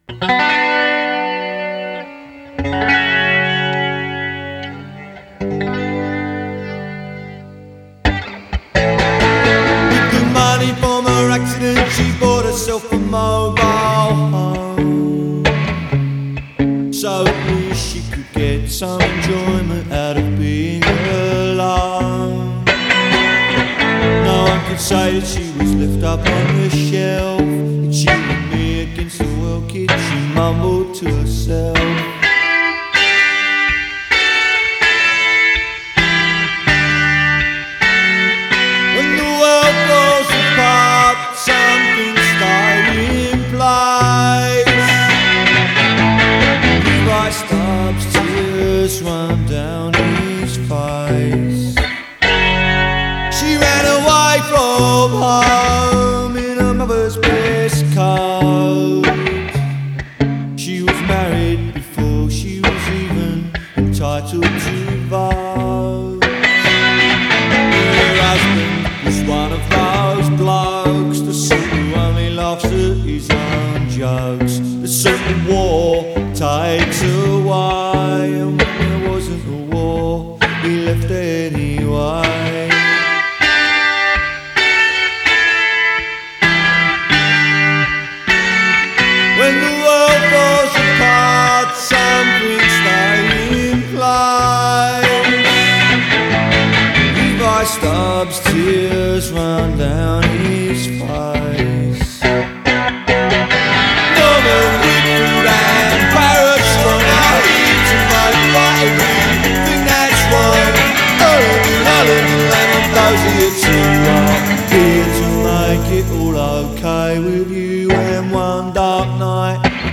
Жанр: Folk, Punk
Исполняет свои песни обычно под электрогитару.